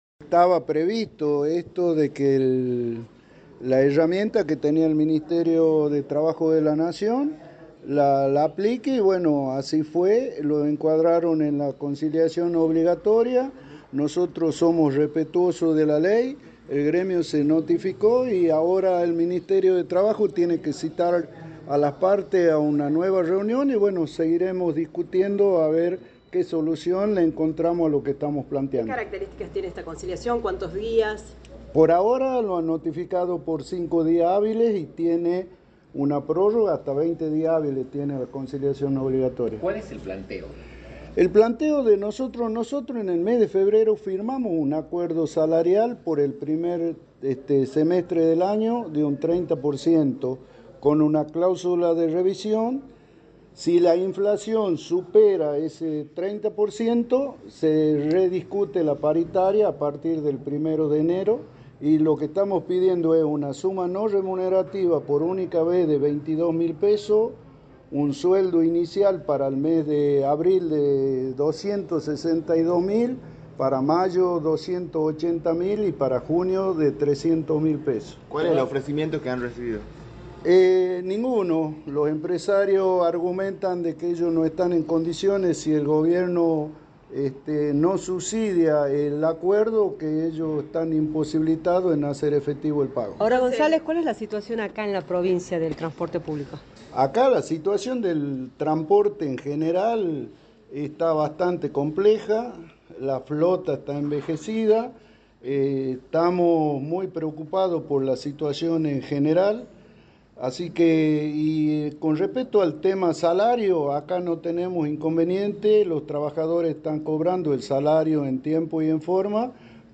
informó en una rueda de prensa la realidad del sector del transporte público de pasajeros